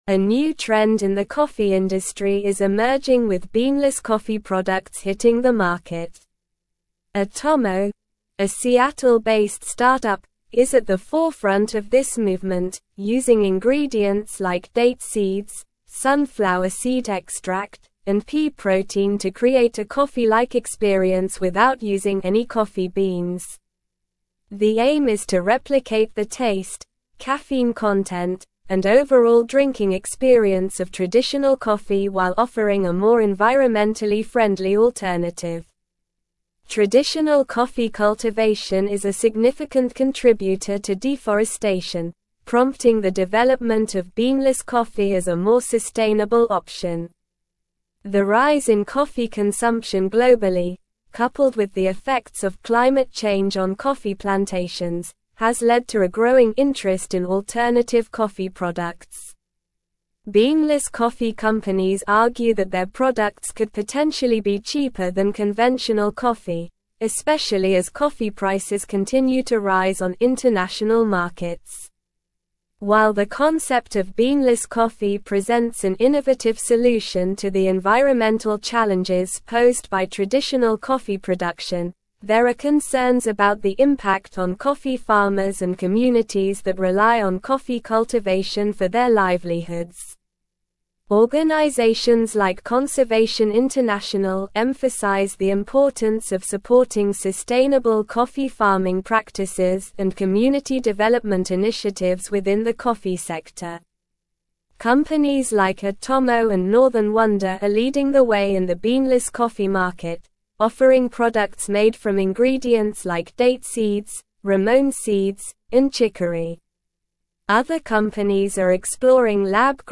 Slow
English-Newsroom-Advanced-SLOW-Reading-Emerging-Trend-Beanless-Coffee-Revolutionizing-the-Industry.mp3